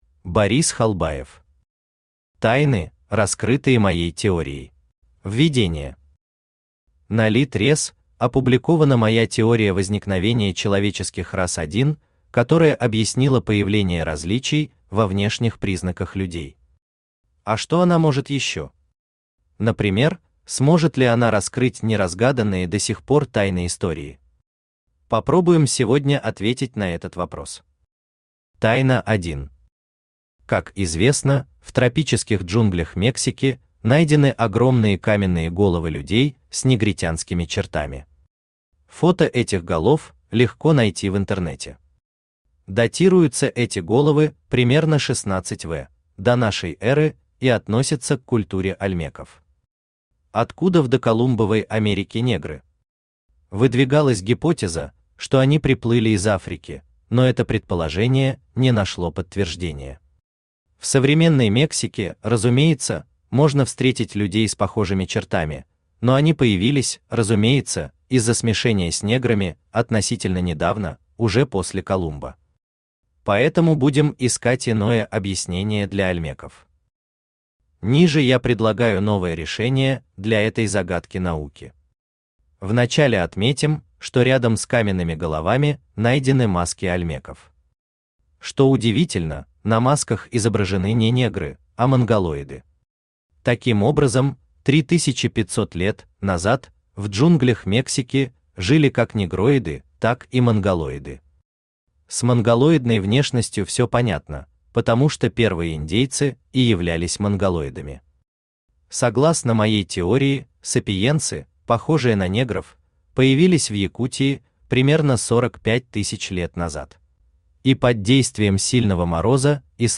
Aудиокнига Тайны, раскрытые моей теорией Автор Борис Халбаев Читает аудиокнигу Авточтец ЛитРес.